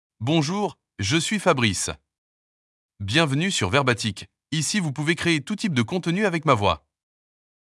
MaleFrench (Switzerland)
FabriceMale French AI voice
Fabrice is a male AI voice for French (Switzerland).
Voice sample
Listen to Fabrice's male French voice.
Fabrice delivers clear pronunciation with authentic Switzerland French intonation, making your content sound professionally produced.